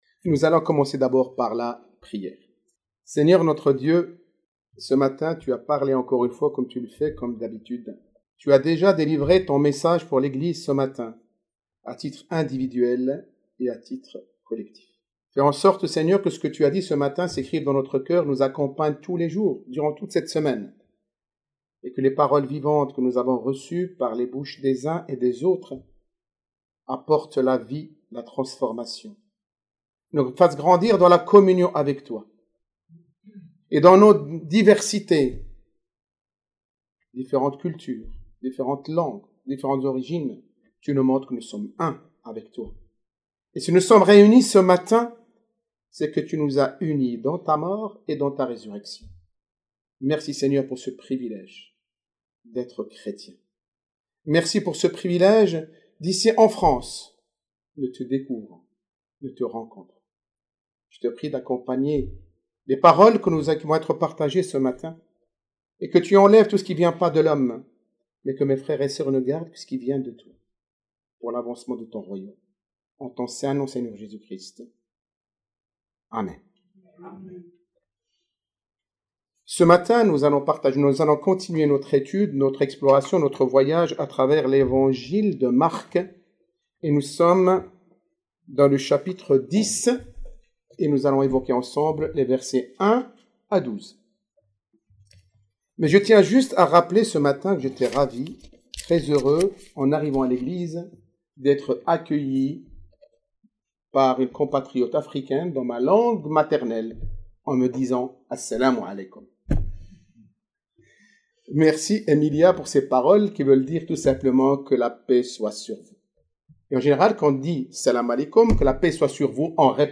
Prédicateurs